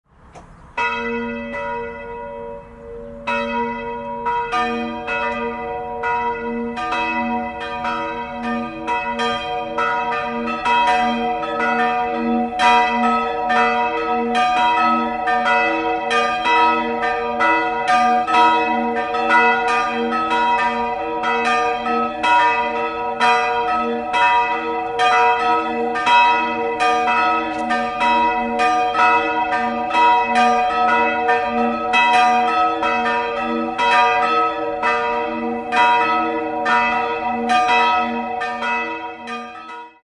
Das von 1737 - 1740 erbaute geräumige Gotteshaus besitzt im Inneren schöne barocke Altäre. Jedes Jahr im Juli findet das St.-Anna-Wallfahrtsfest statt. 3-stimmiges Geläute: h'-dis''-e'' Nähere Daten liegen nicht vor.